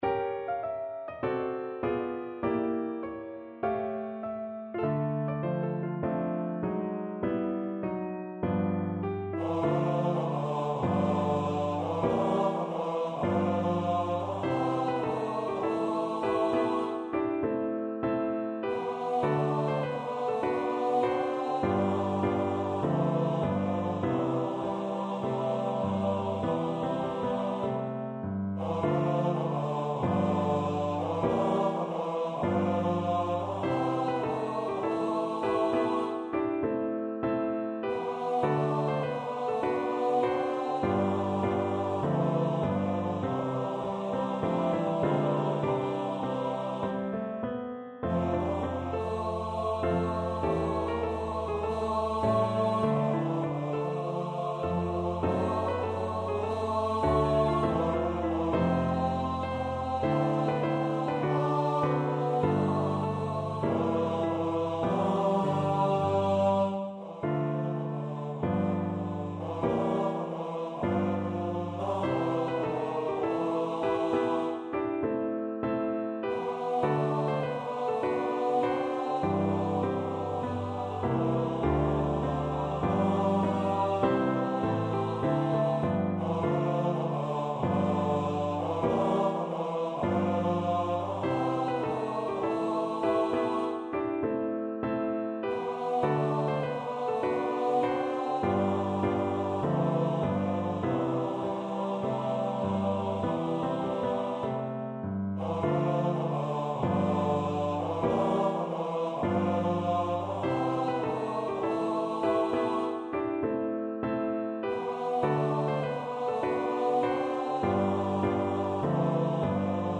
AS TIME GOES BY TTB full – Full Score